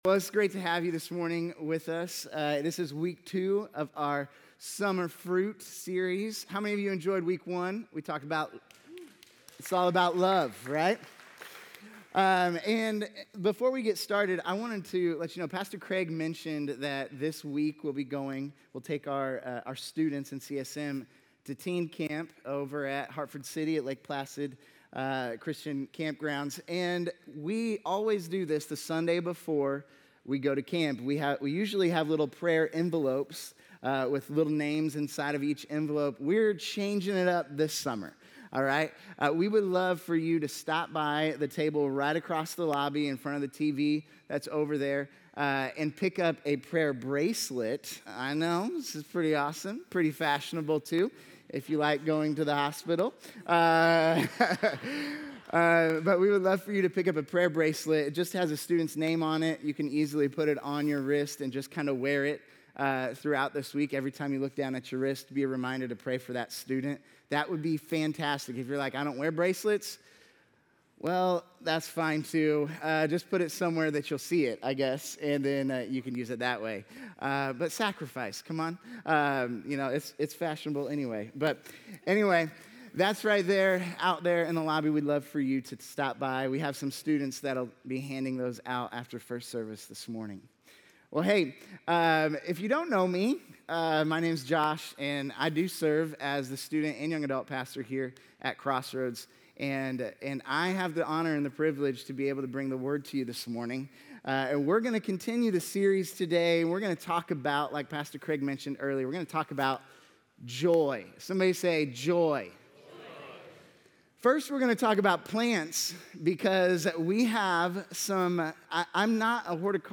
A message from the series "Summer Fruit."